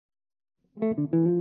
Il risultato in ogni caso non pare giovarne.